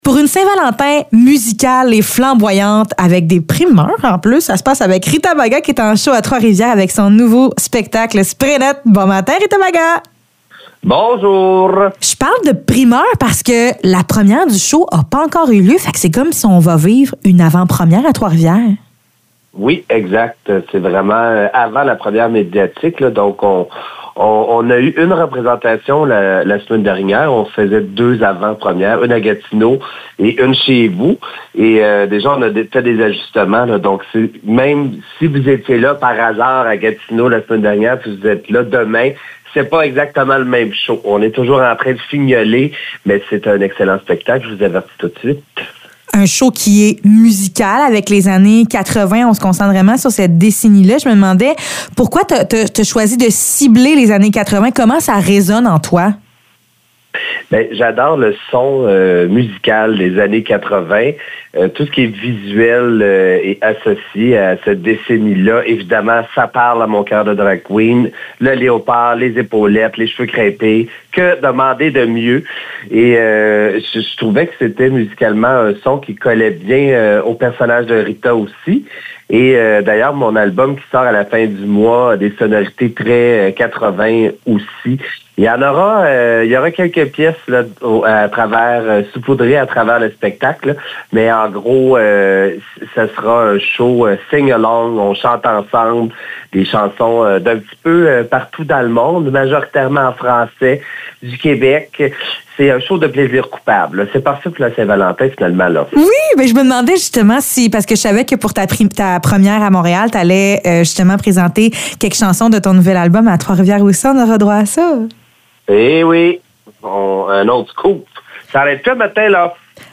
Entrevue avec Rita Baga